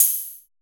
HOUSE OHH.wav